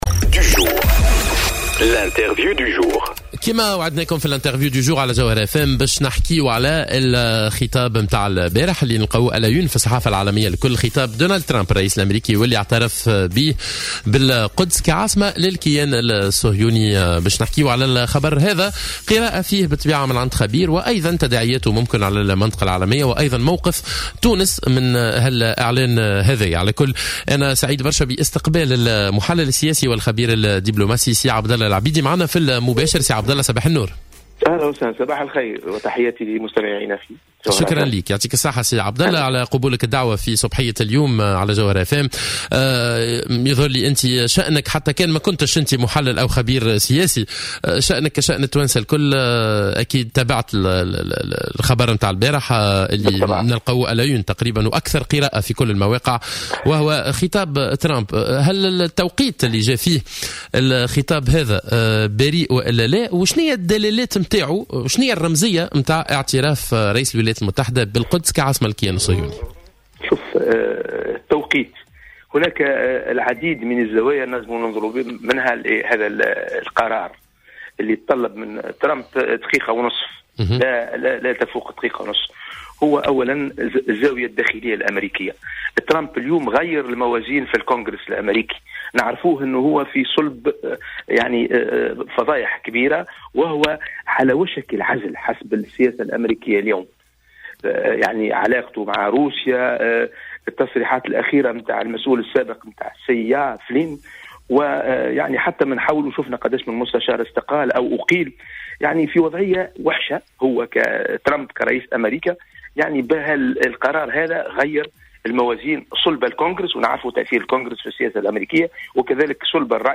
ودعا إلى اهمية اللجوء إلى المؤسسات الدولية والاحتكام إلى القانون بدلا من الصراخ، بحسب تعبيره. ولاحظ في برنامج "صباح الورد" على "الجوهرة أف أم" أن دونالد ترامب يعتبر في وضعية صعبة داخل ادارته وبهذا القرار فقد غيّر المعادلة لصالحه بالنظر الى أهمية اللوبي المساند لإسرائيل في الولايات المتحدة.